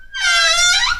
CookerOpen.ogg